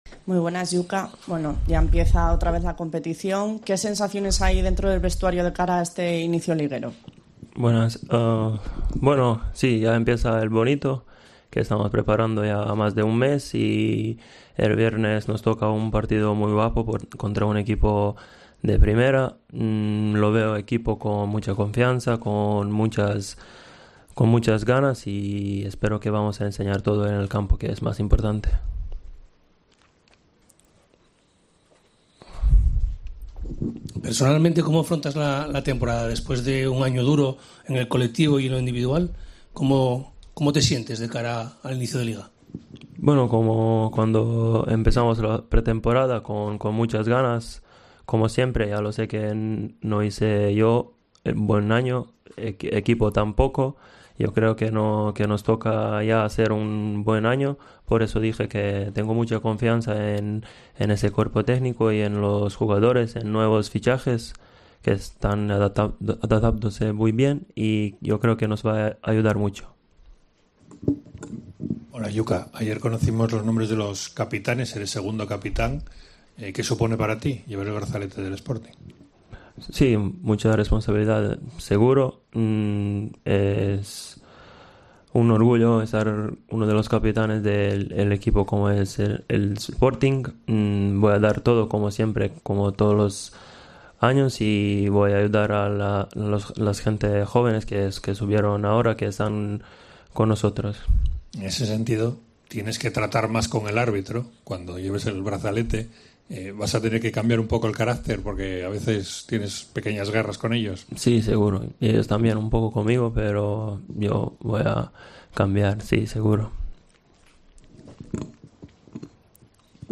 Después de una temporada discreta, el delantero tiene aires de revancha. Este miércoles ha pasado por sala de prensa al término del entrenamiento en Mareo.